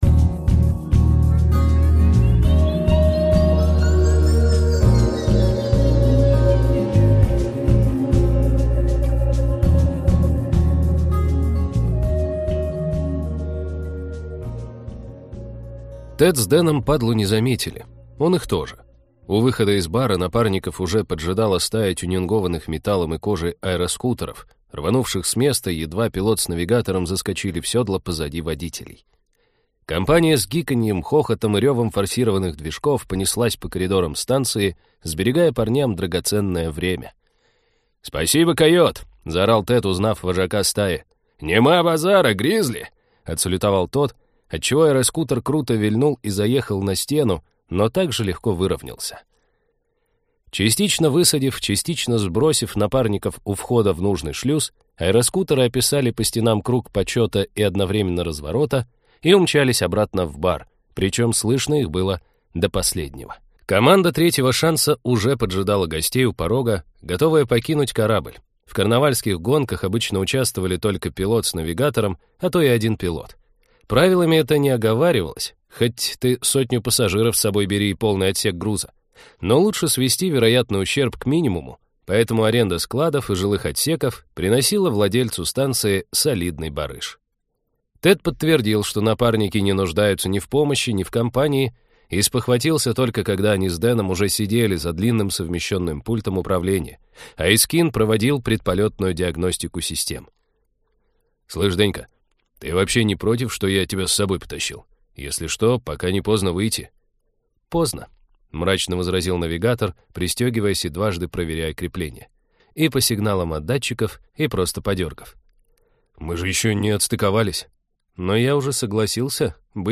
Аудиокнига Космотехнолухи, том 2
Качество озвучивания на высоте (сами убедитесь).